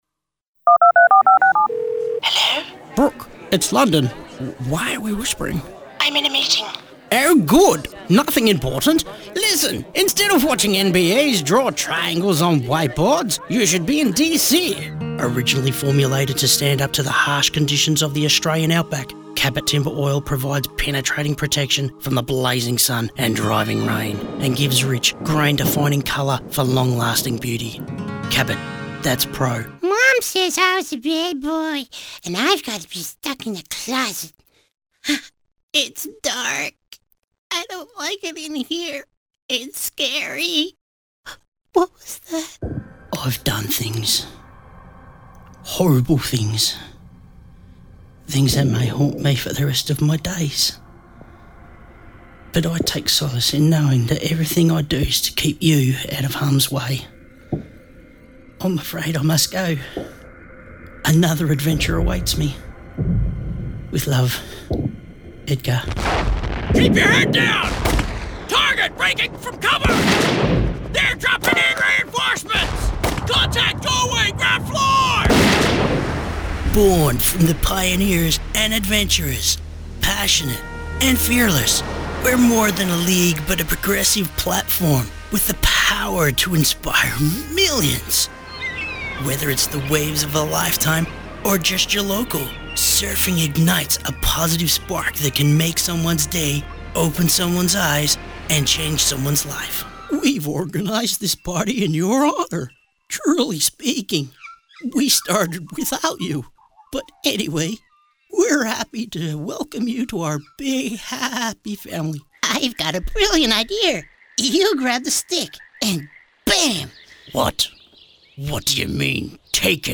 With many sounds, character dispositions fit for purpose.
Compilation Demo